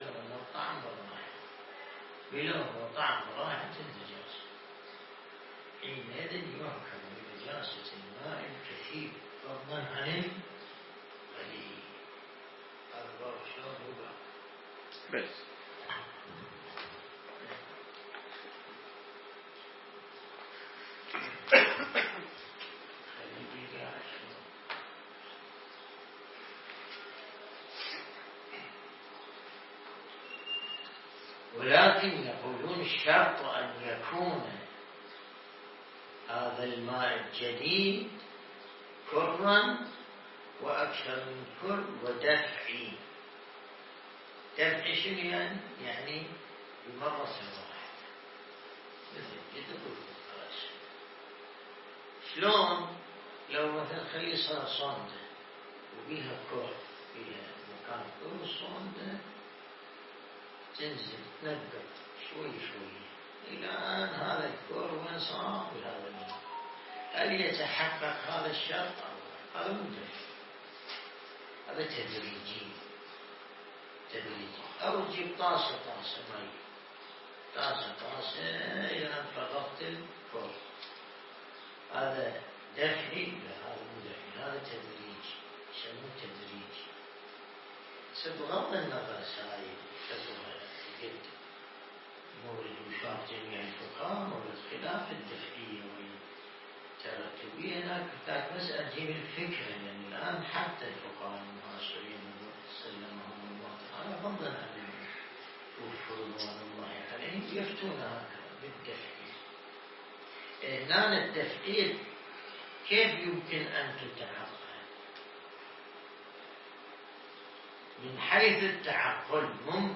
الدرس الاستدلالي شرح بحث الطهارة من كتاب العروة الوثقى